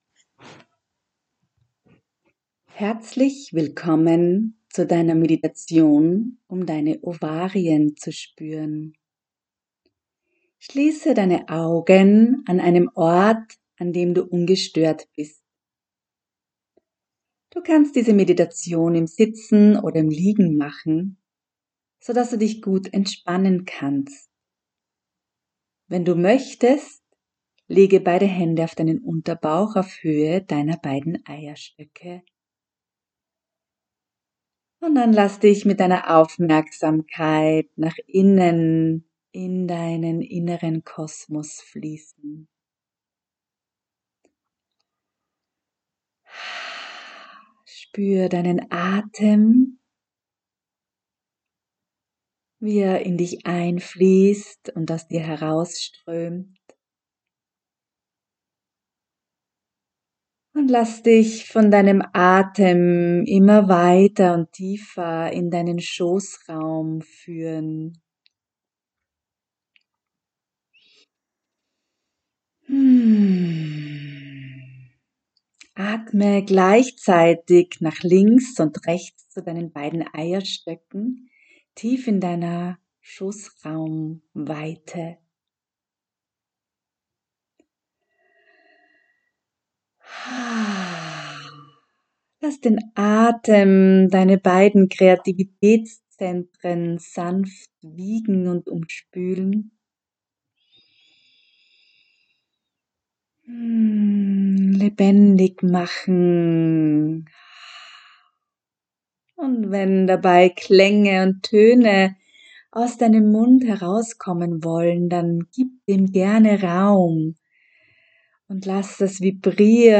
Audio-Meditationen aus dem Buch
Meditation-Ovarien-spueren-1.mp3